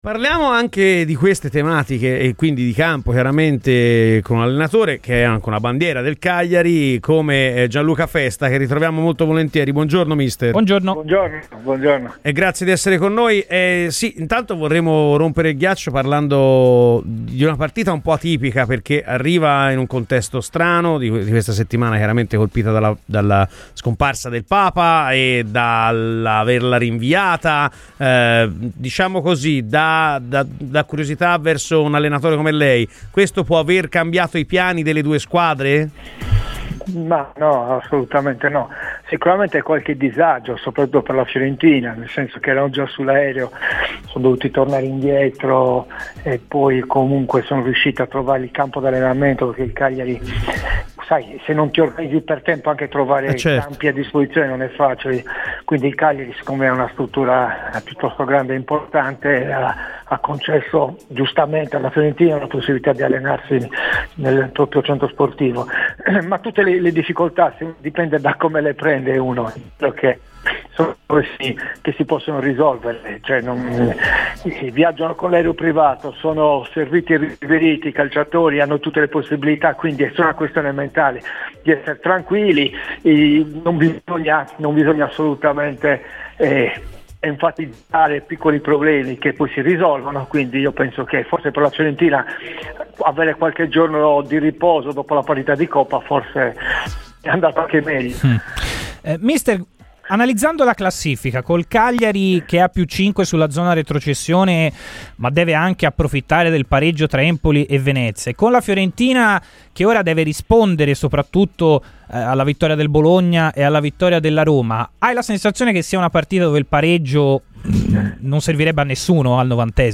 Gianluca Festa, allenatore cresciuto nelle giovanili del Cagliari ed ex giocatore rossoblu, ha parlato nel corso di Chi si compra? su Radio FirenzeViola “Tutte e due le squadre cercheranno di vincere la partita.